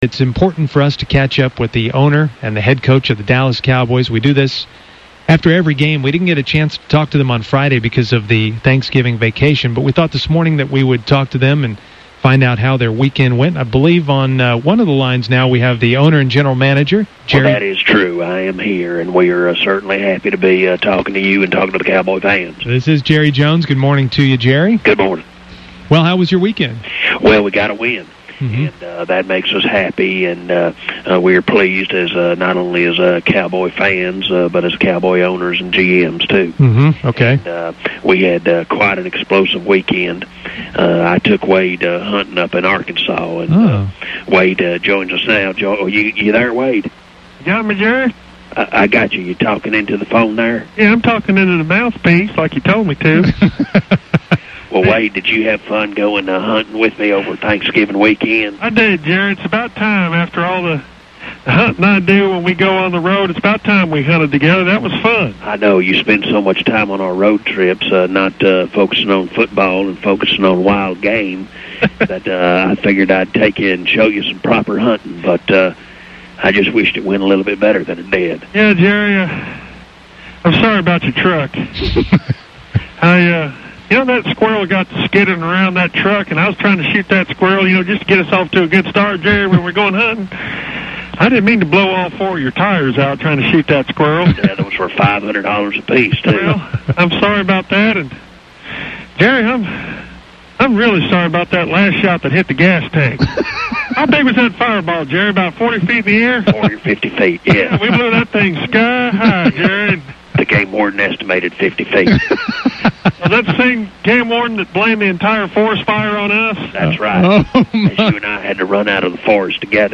Weekly visit with the fake Jerry Jones and fake Wade Phillips.